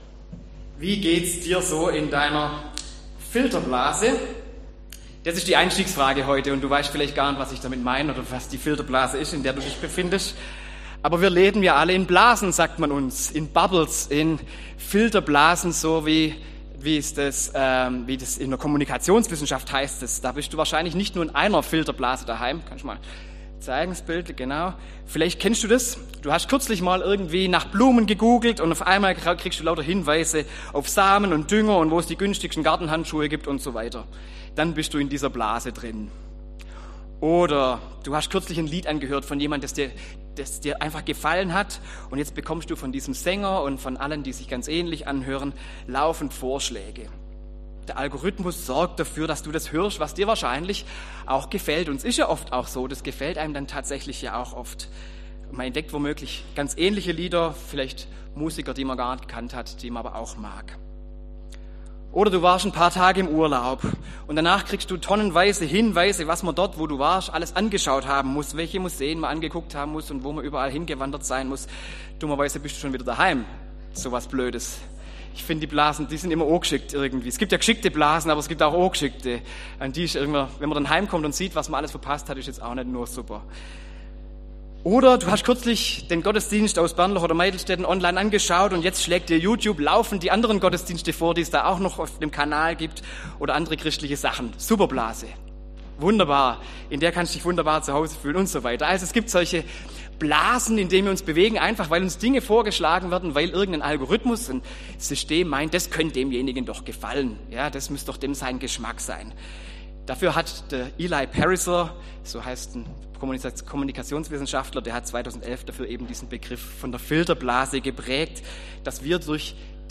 Predigt am 1. Sonntag nach Trinitatis